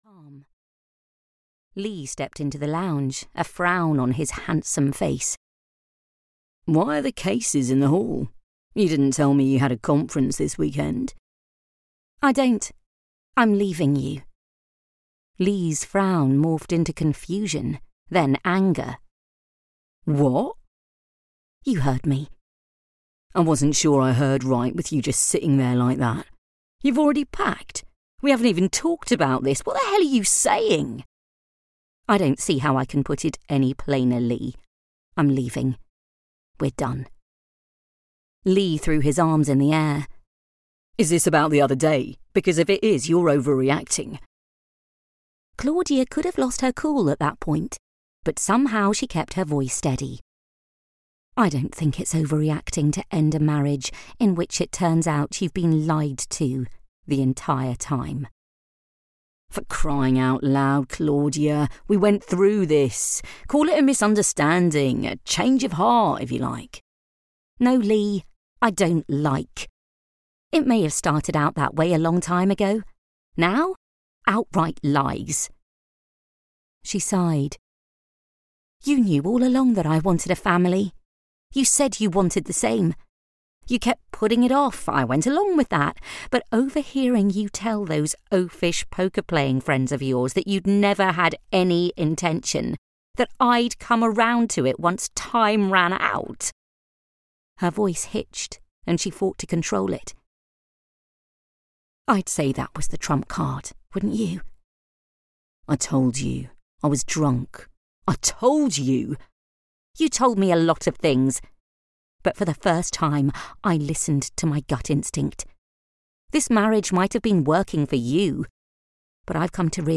The Little Shop in Cornwall (EN) audiokniha
Ukázka z knihy